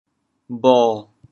潮阳拼音“bho7”的详细信息
国际音标 [bo]
bho7.mp3